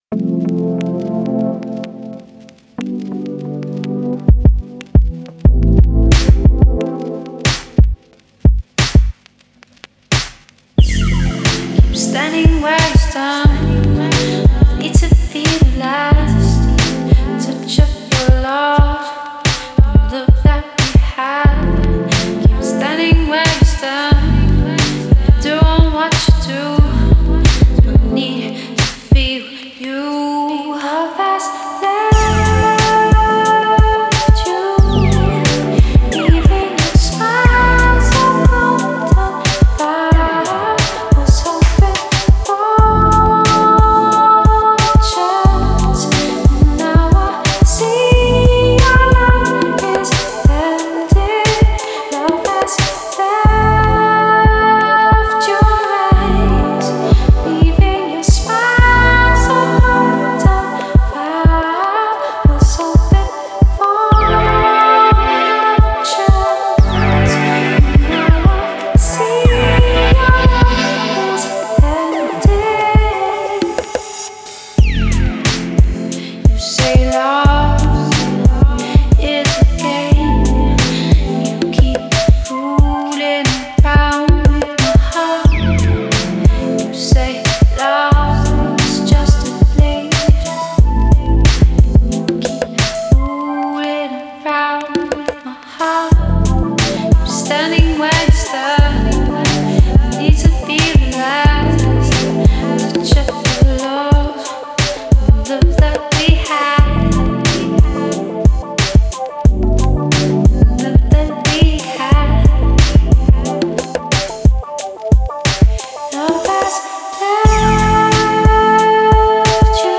Genre: Chill Out.